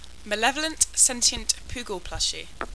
Pronunciation: MSPP - mal-ev-oh-lent sen-tee-ent poo-gull plush-ee Neopets TCG: Malevolent Sentient Poogle Plushie , Malevolent Sentient Poogle Plushie (2nd Print) Caption Competition: #714 How To Draw: The Malevolent Sentient Poogle Plushie